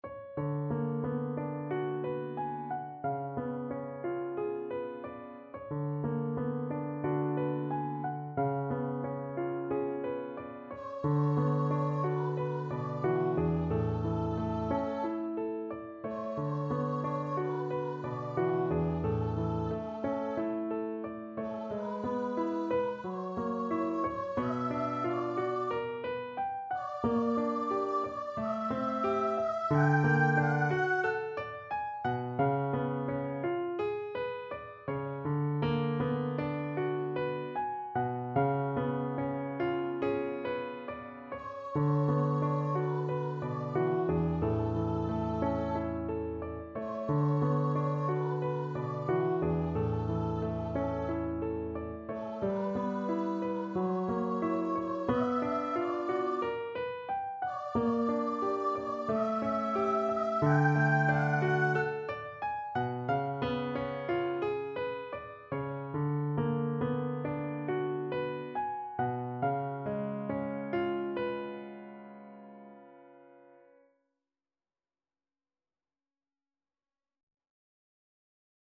~ = 45 Langsam, zart
2/4 (View more 2/4 Music)
F#5-G6
Classical (View more Classical Voice Music)